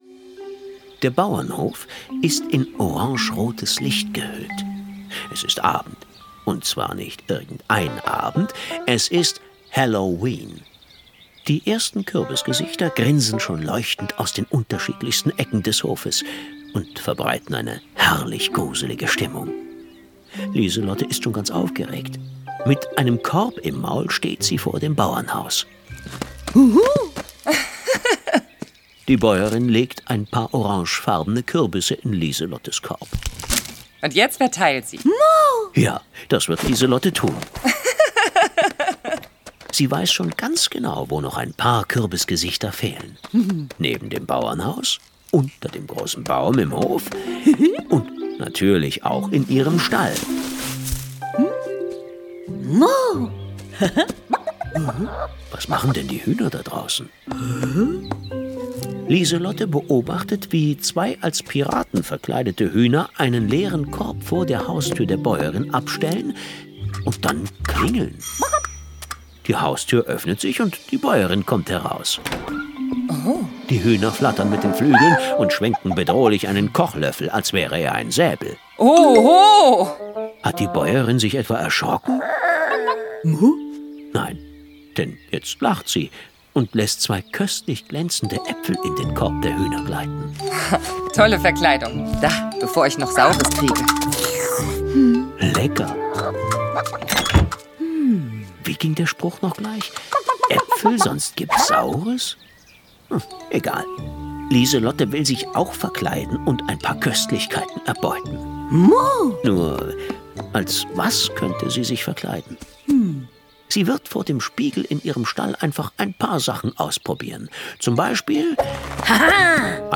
Die Originalhörspiele zur TV-Serie!